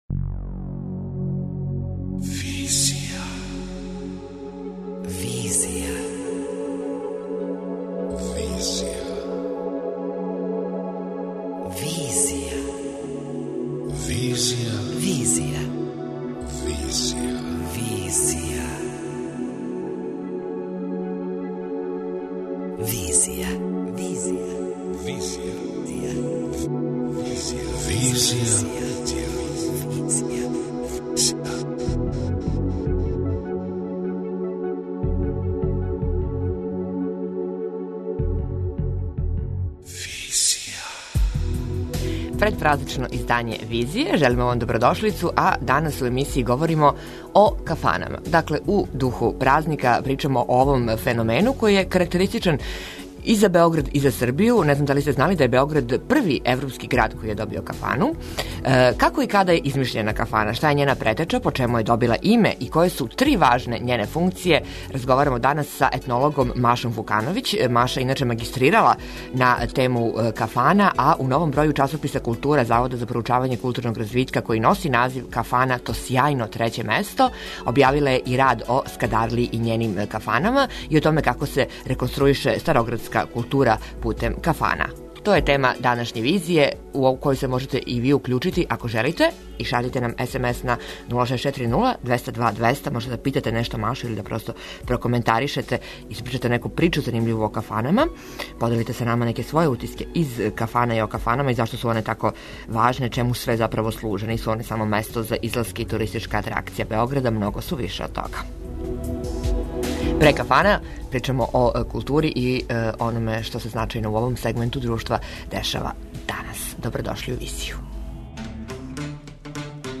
преузми : 27.89 MB Визија Autor: Београд 202 Социо-културолошки магазин, који прати савремене друштвене феномене.